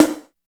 51 SNARE 4.wav